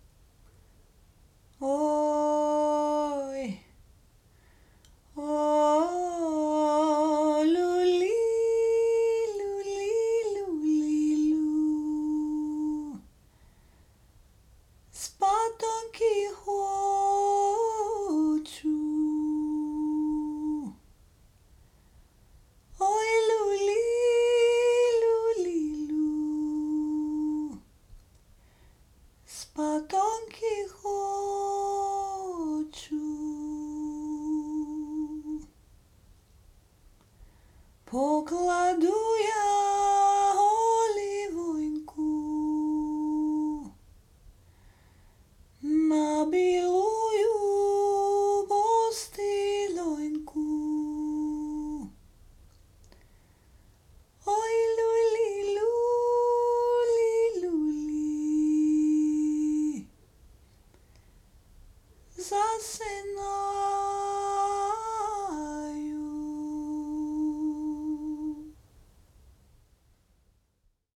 Ukrainian lullaby